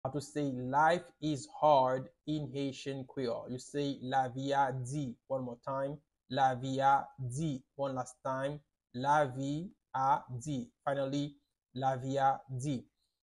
How to say Life is Hard in Haitian Creole - Lavi a di pronunciation by a native Haitian Teacher
“Lavi a di” Pronunciation in Haitian Creole by a native Haitian can be heard in the audio here or in the video below:
How-to-say-Life-is-Hard-in-Haitian-Creole-Lavi-a-di-pronunciation-by-a-native-Haitian-Teacher.mp3